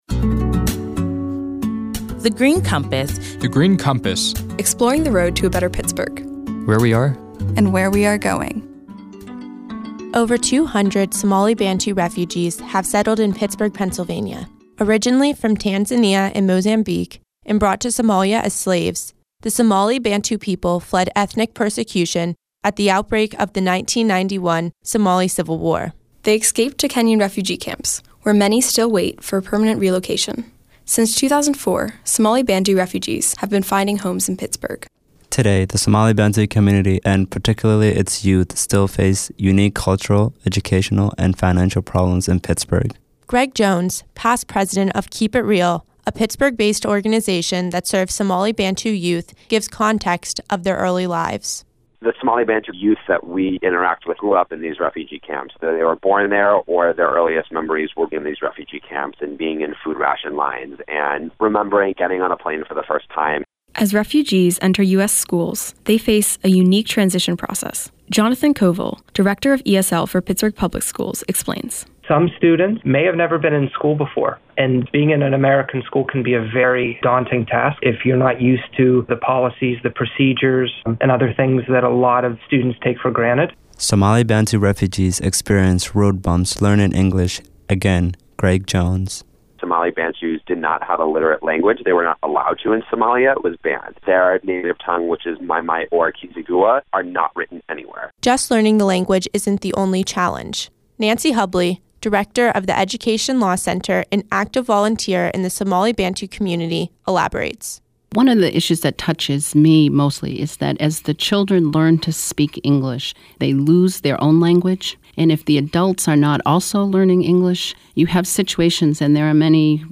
In July 2015, twenty-one recent high-school graduates created these radio features while serving as Summer Interns at The Heinz Endowments.